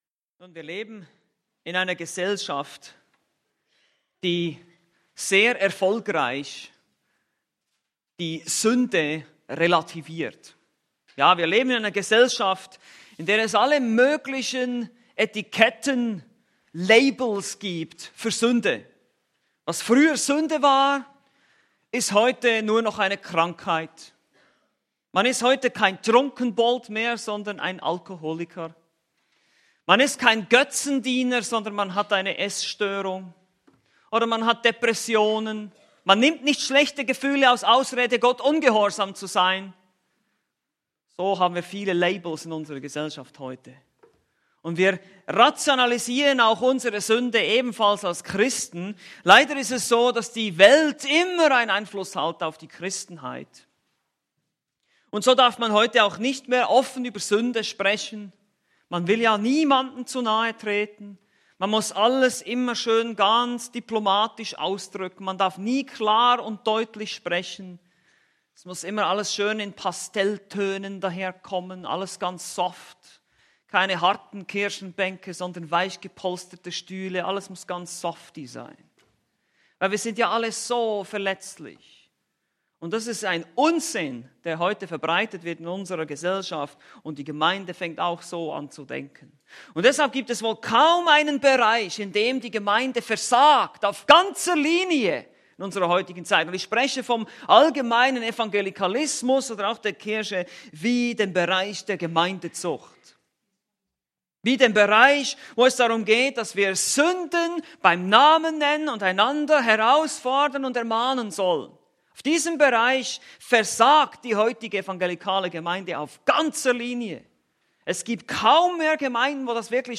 Predigt: "1.